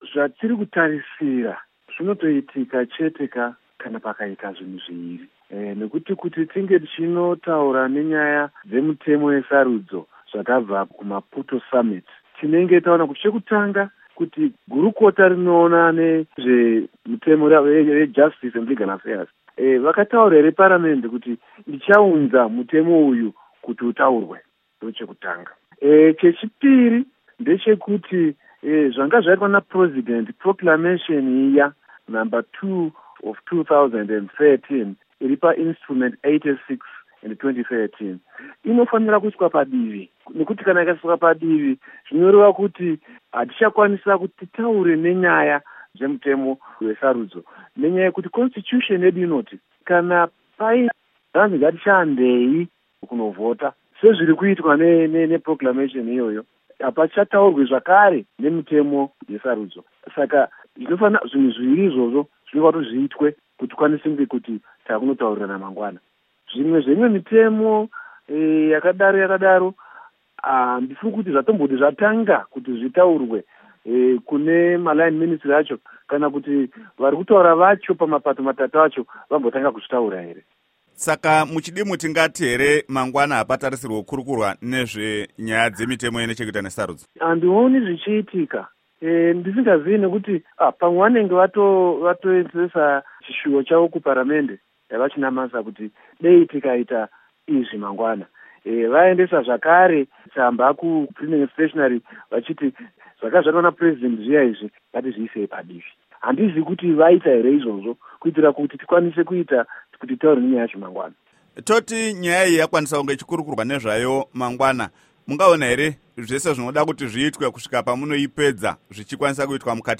Hurukuro naVaEric Matinenga